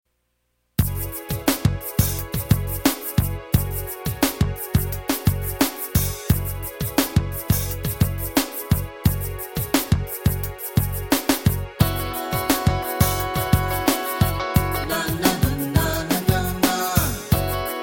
A Multicultural Music Appreciation Song